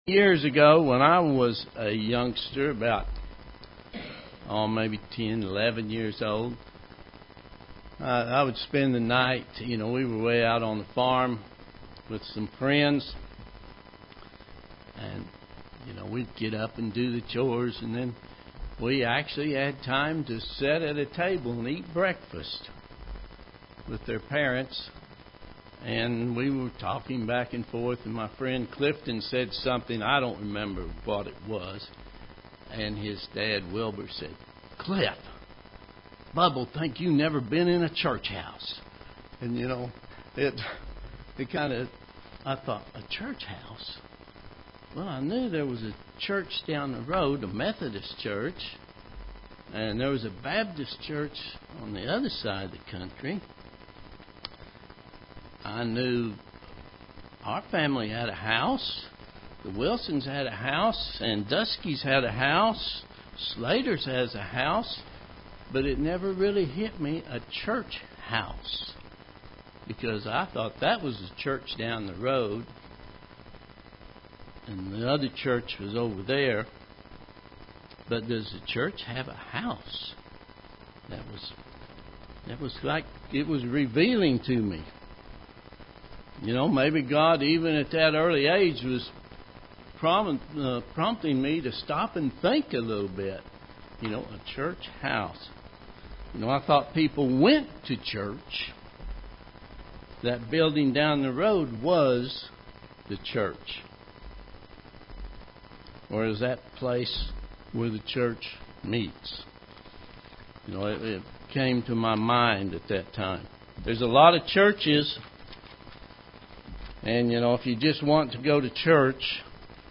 UCG Sermon Studying the bible?
Given in Oklahoma City, OK